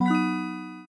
Powerup/success